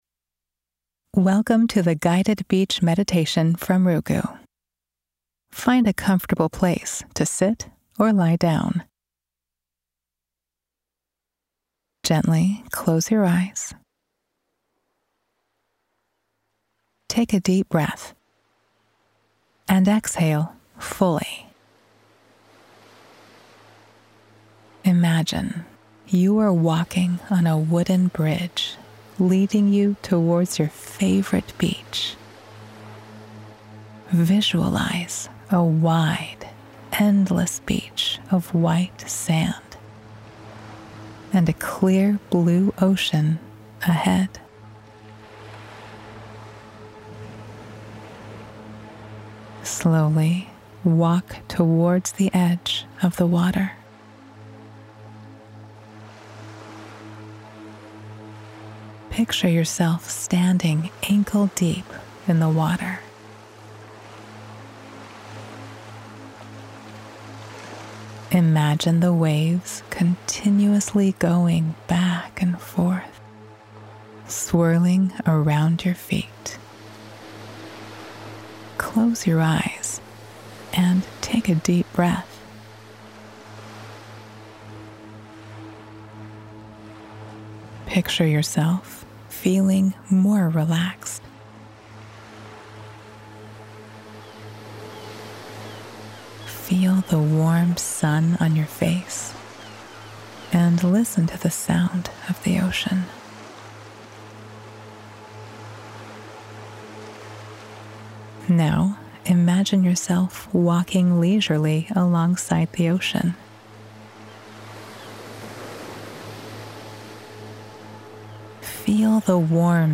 Meditate – Guided Imagery Beach